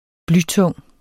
blytung adjektiv Bøjning -t, -e Udtale [ ˈblyˌtɔŋˀ ] Betydninger 1. tung som bly; meget tung Snit et hvidkålshoved fint og læg det i blytungt pres med masser af knust enebær i en gryde WeekA2013 Weekendavisen (avis), 2013.